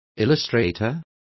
Complete with pronunciation of the translation of illustrators.